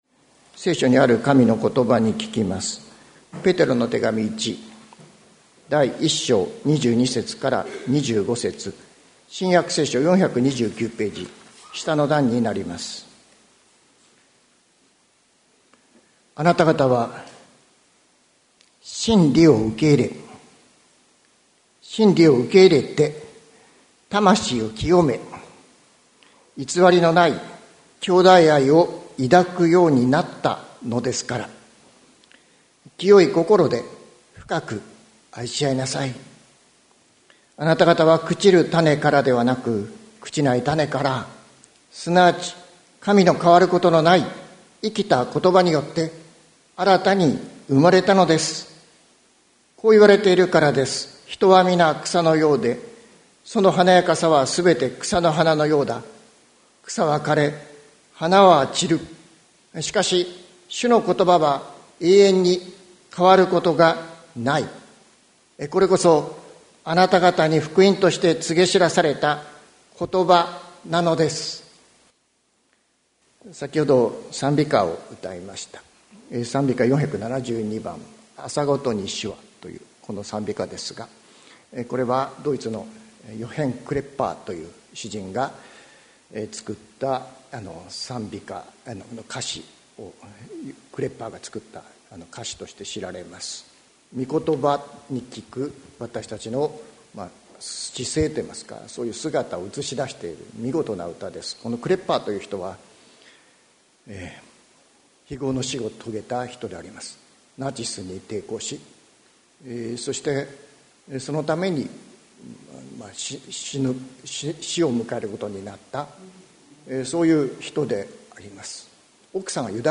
2024年09月01日朝の礼拝「滅びない永遠のことば」関キリスト教会
説教アーカイブ。